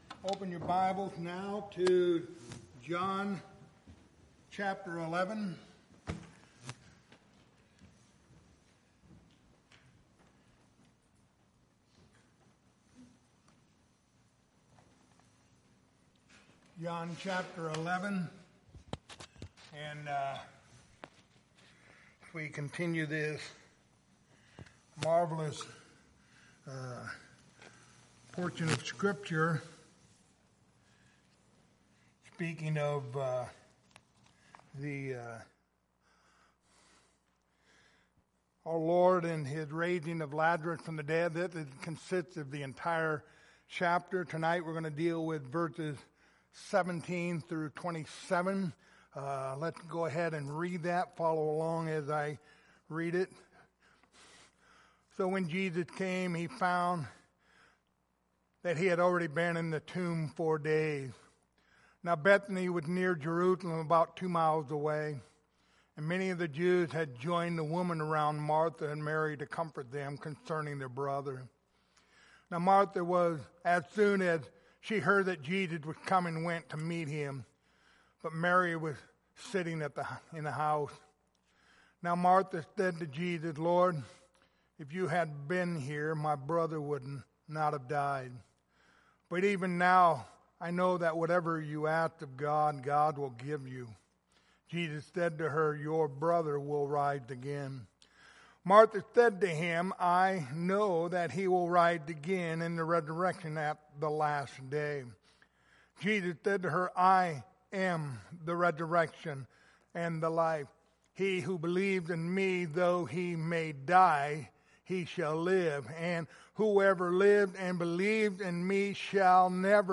Passage: John 11:17-27 Service Type: Wednesday Evening